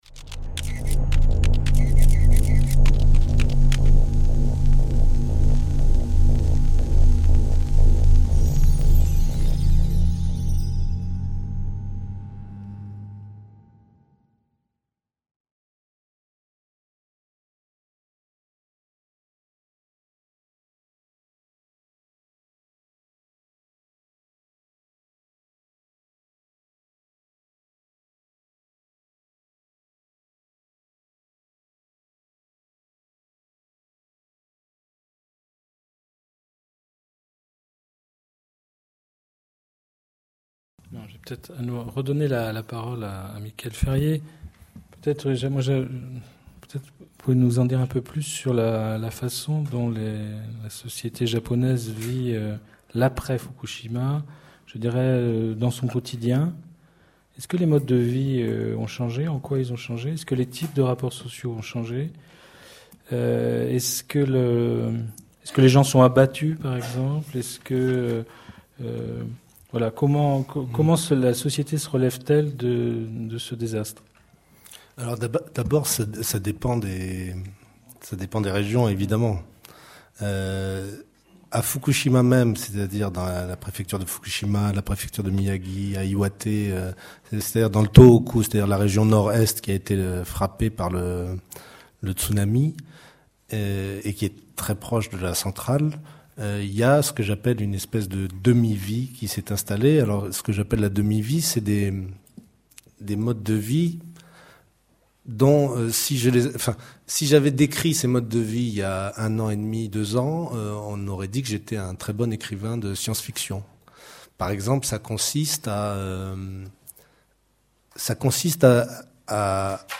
Débat dans le cadre du cycle Rendez-vous de crise organisé par les Cercles de formation de l'EHESS.